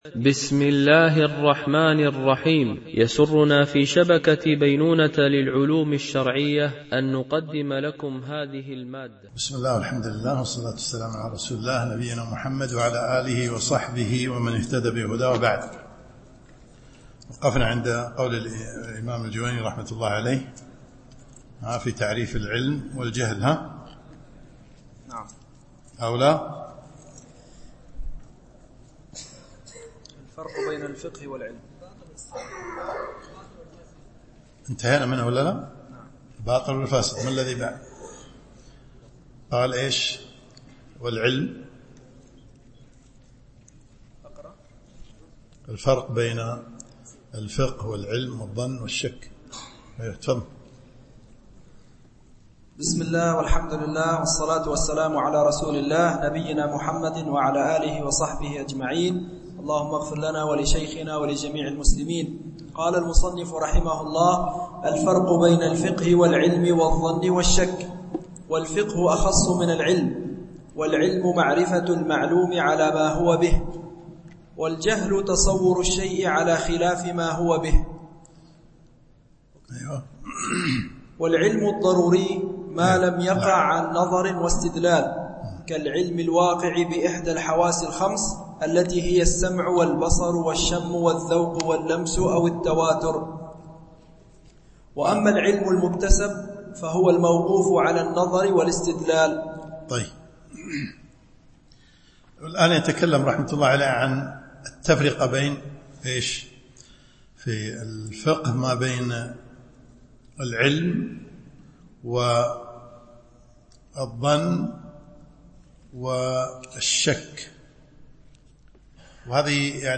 شرح الورقات في أصول الفقه للجويني ـ الدرس 3
Mono